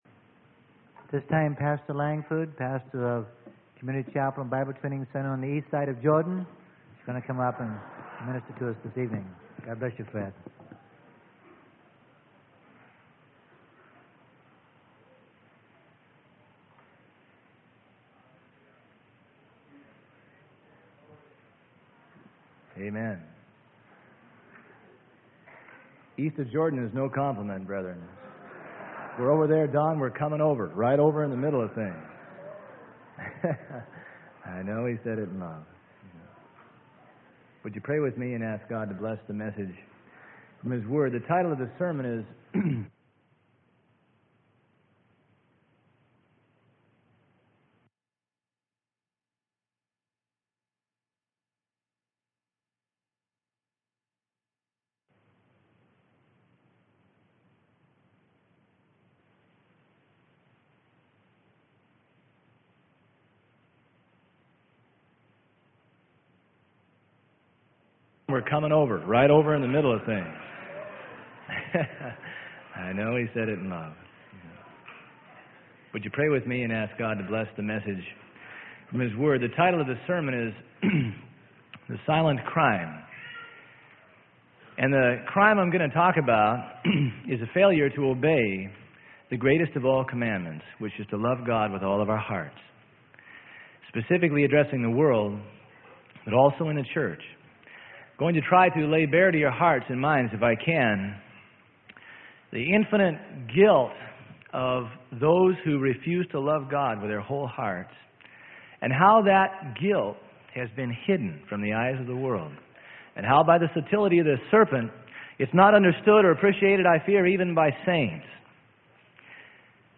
Sermon: THE SILENT CRIME - Freely Given Online Library